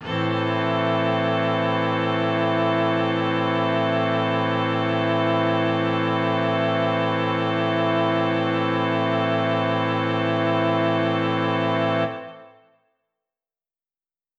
SO_KTron-Cello-Amin.wav